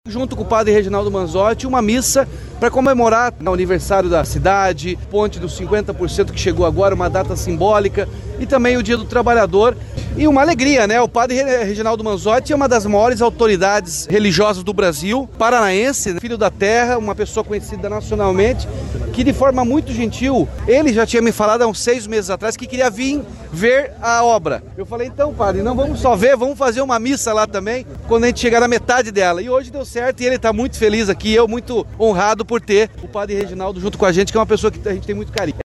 Após um ano e sete meses, a obra da Ponte de Guaratuba está com metade dos trabalhos concluídos, segundo o governo do estado. A etapa foi comemorada com a celebração de uma missa, presidida pelo Padre Reginaldo Manzotti, no canteiro de obras, na manhã desta quinta-feira (1º), Dia do Trabalhador, e também na semana do aniversário da cidade de Guaratuba, como destacou o governador Ratinho Junior (PSD).